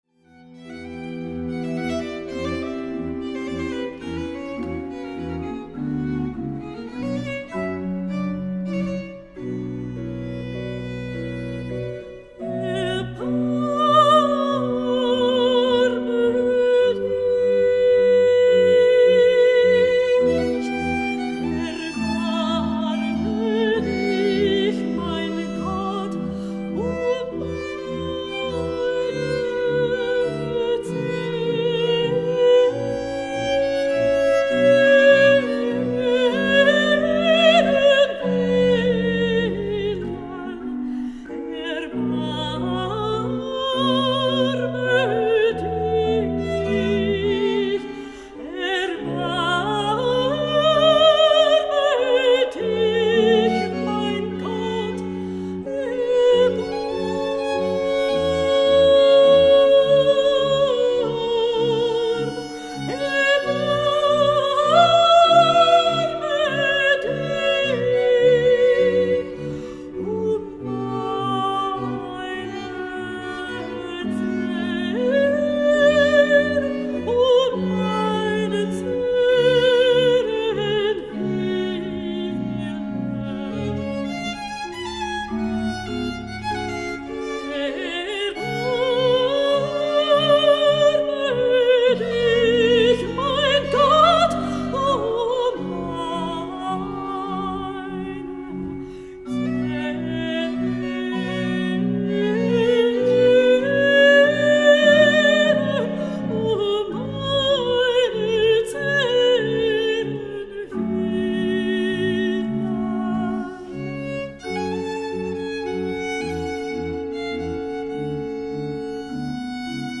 CLASSIQUE
Baroque
Orgue
Violon
l’église Immaculée-Conception